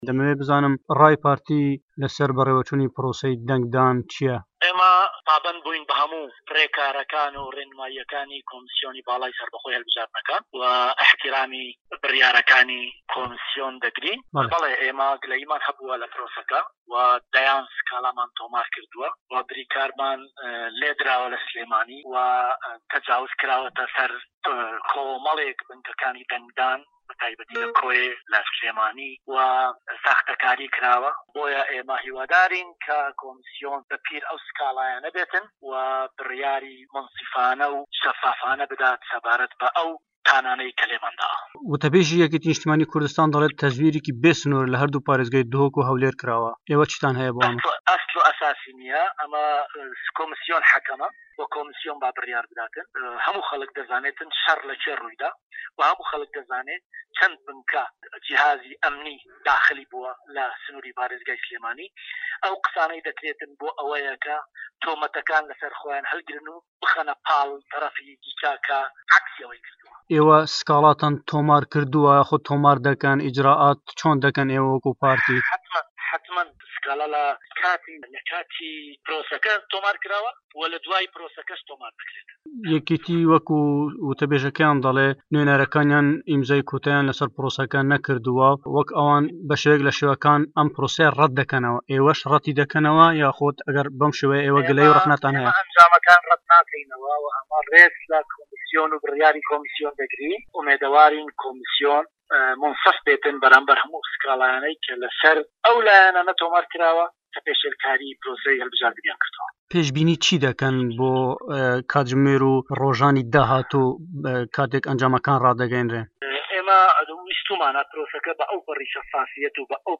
لێدوانی خەسرەو گۆران